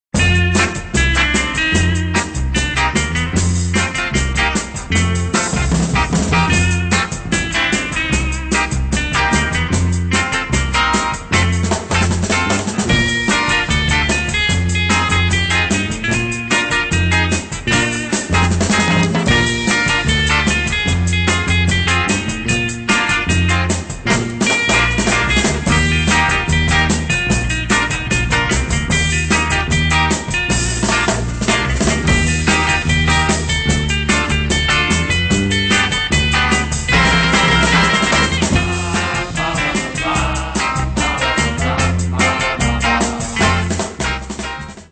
funny medium instr.